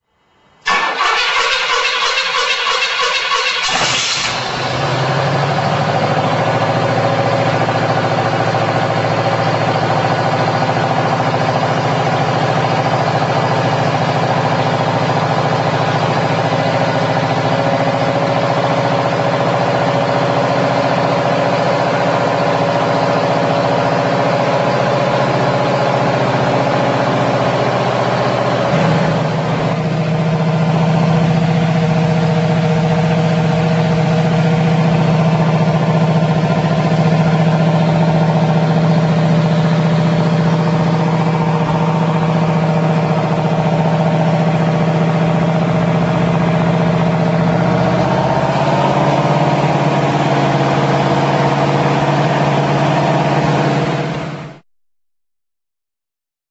The car would start fairly satisfactorily, run somewhat choppy (pronounced cylinder firing) for a few minutes at 1400-1500, then would surge to 1800-1900 and run smooth as silk. Turns out to have been a plethora of vacuum leaks, and replacing all vacuum lines smoothed everything out, although an occasional puff is still heard from the exhaust when listening up close.
78tastart.rm